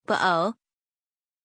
Pronunciation of Bo
pronunciation-bo-zh.mp3